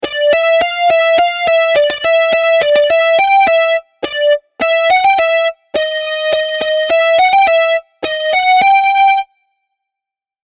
1. Clap back rhythms